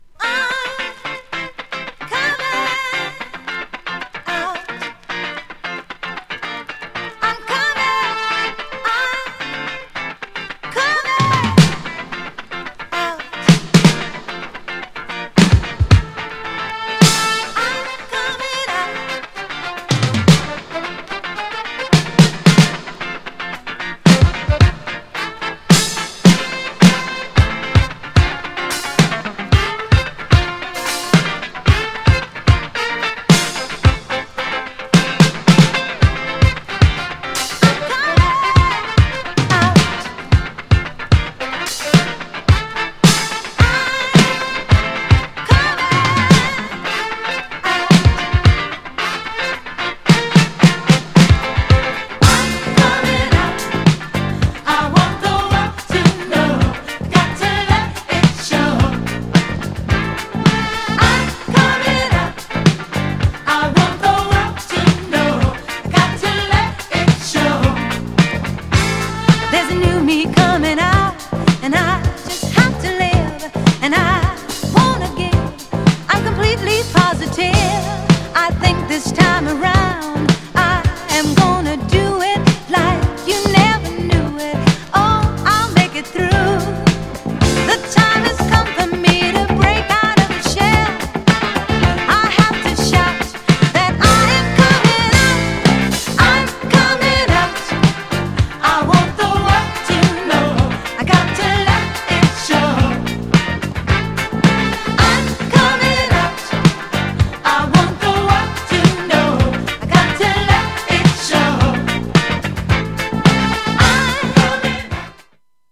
GENRE Dance Classic
BPM 106〜110BPM